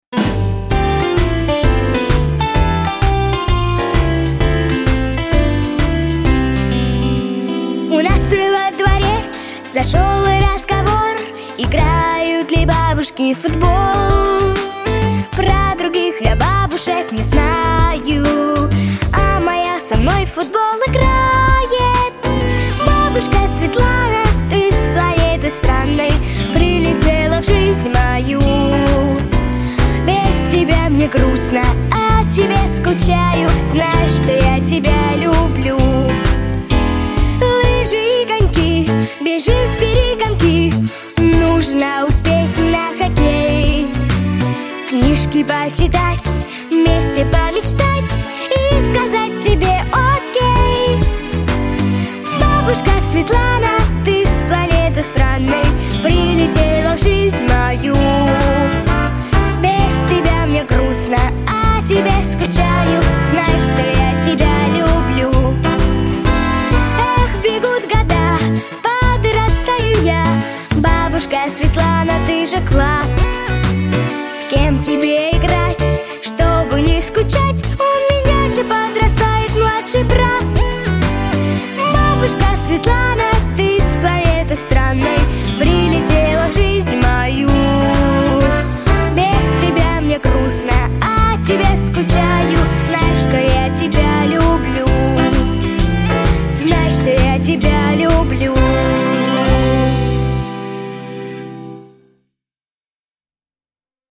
• Категория: Детские песни / Песни про бабушку